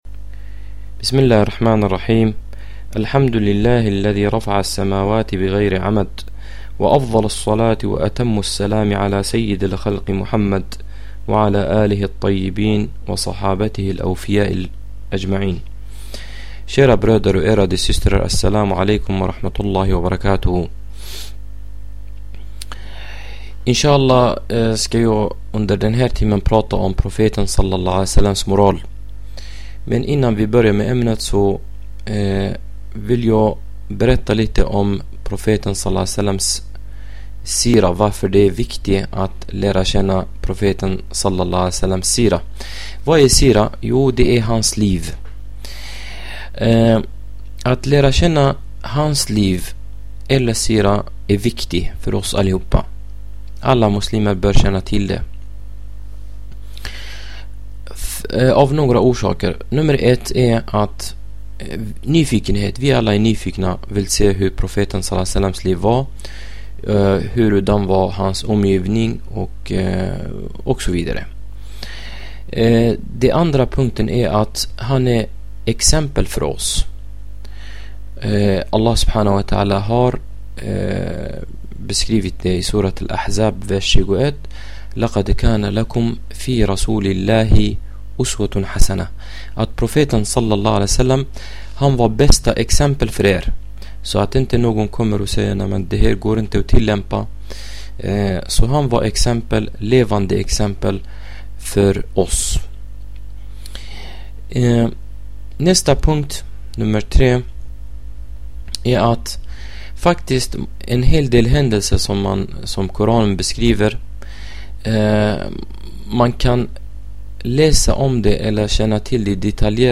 Ett föredrag om Profeten Mohammeds (fvmh)s moral